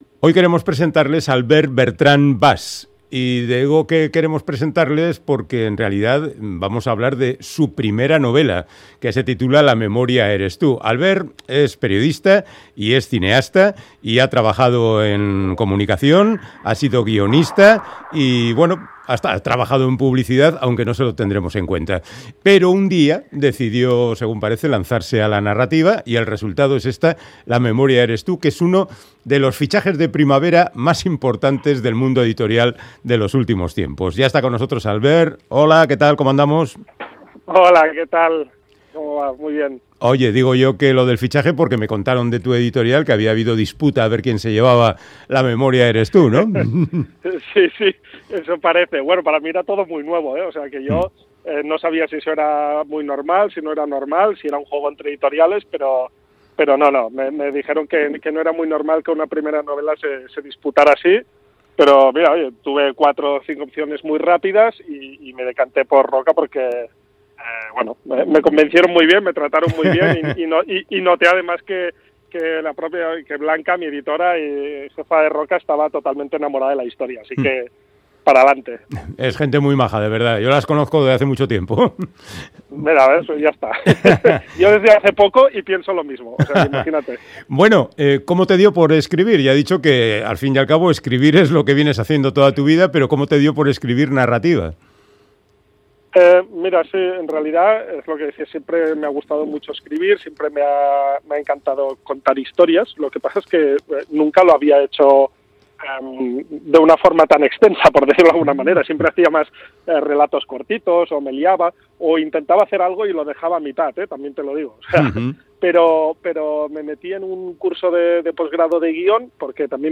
Charlamos con el periodista catalán